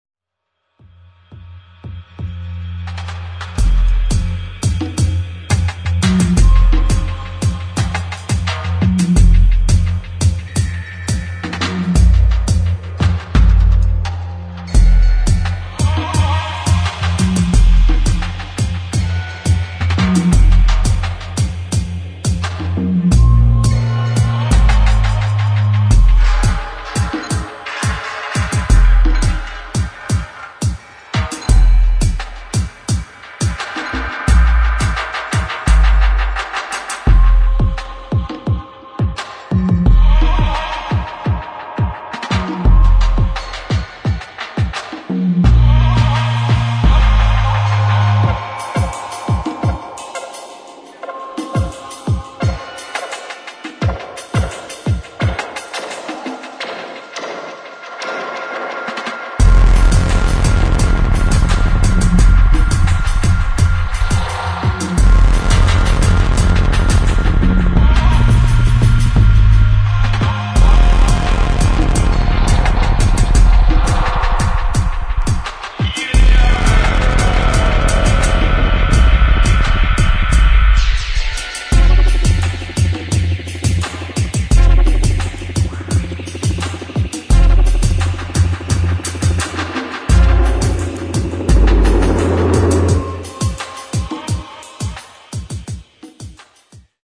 [ DRUM'N'BASS / DUBSTEP / BASS ]